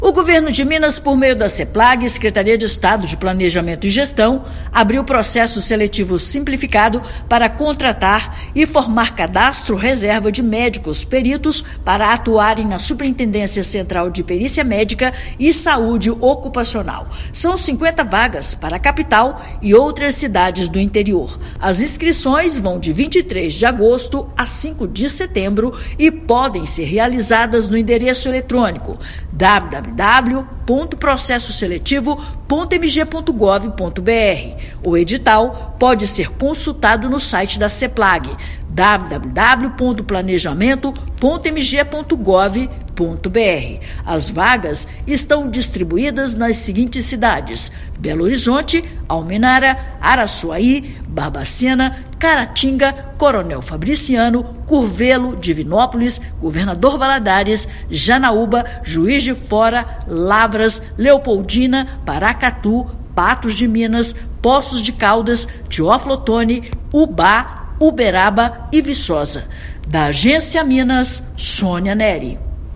Profissionais irão atuar na área de perícia médica e saúde ocupacional do Governo; Inscrições vão de 23/8 a 5/9. Ouça matéria de rádio.